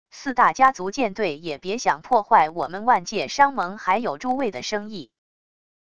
四大家族舰队也别想破坏我们万界商盟还有诸位的生意wav音频生成系统WAV Audio Player